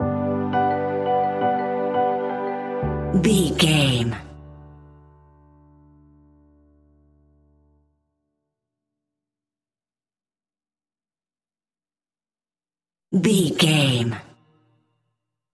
Ionian/Major
D
fun
energetic
uplifting
instrumentals
indie pop rock music
upbeat
groovy
guitars
bass
drums
piano
organ